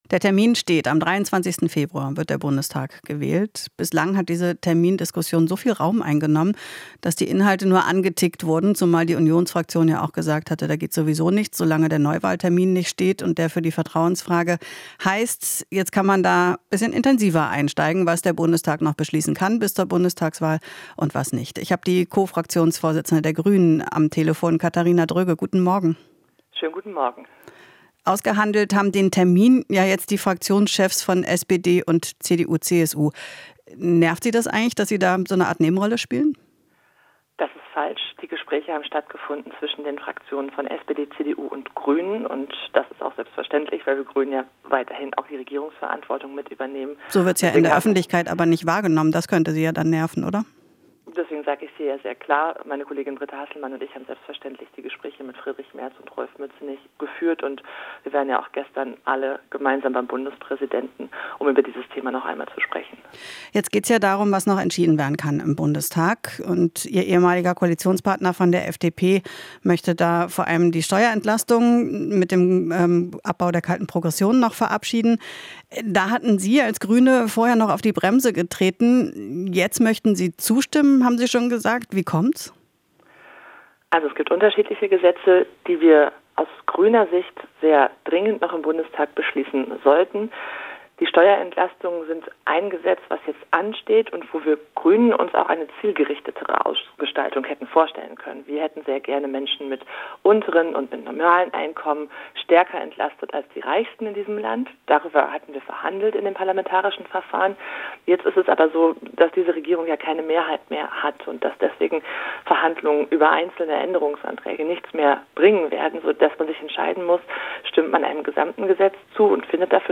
Interview - Dröge (Grüne): Wollen noch Entscheidungen gemeinsam treffen